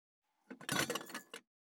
212,机に物を置く,テーブル等に物を置く,食器,グラス,コップ,工具,小物,雑貨,コトン,
コップ効果音物を置く